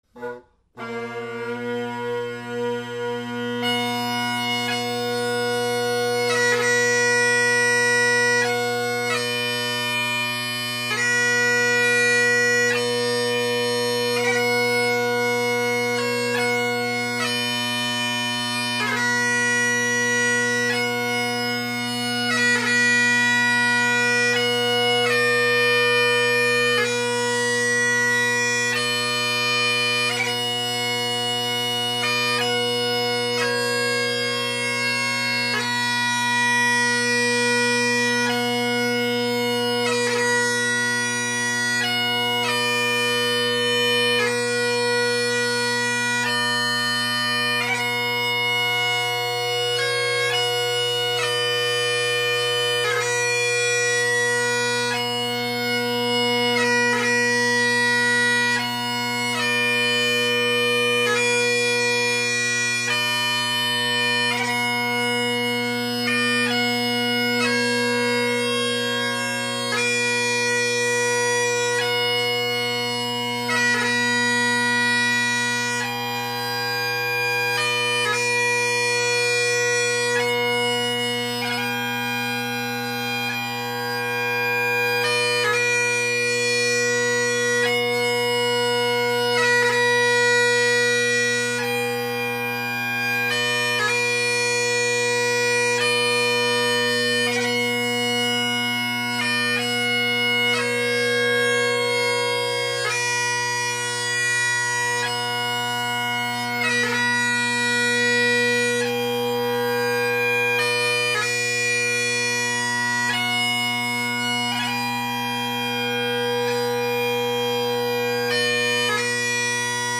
I don’t play enough Piobaireachd
Glencoe bagpipes, Colin Kyo chanter, straight cut Husk chanter reed, Canning drone reeds (carbon fiber bass).
Great Highland Bagpipe Solo